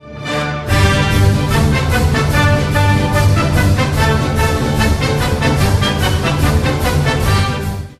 French_cancan.oga